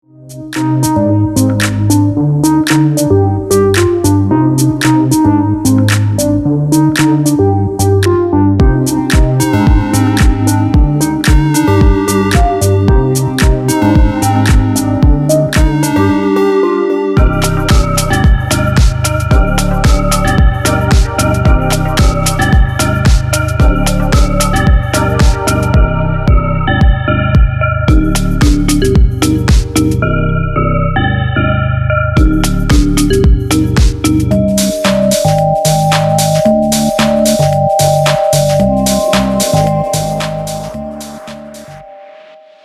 • Качество: 192, Stereo
deep house
атмосферные
без слов
Electronica
космические
металлофон